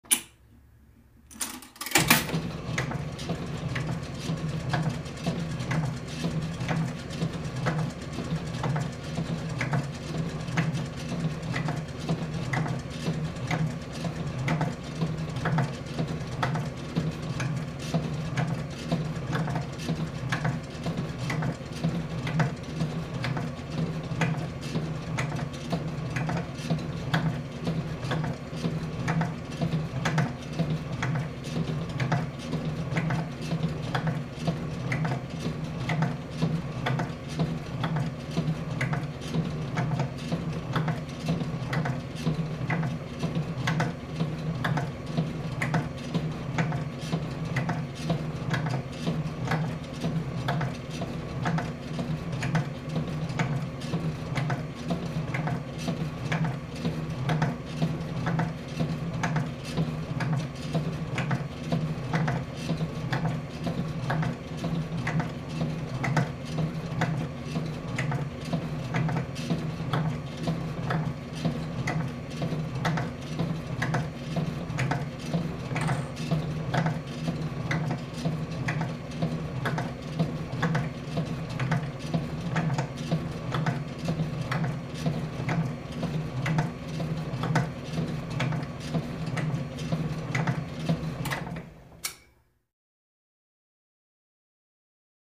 Rocking Horse Old Electric; On, Steady Clunky Run, Off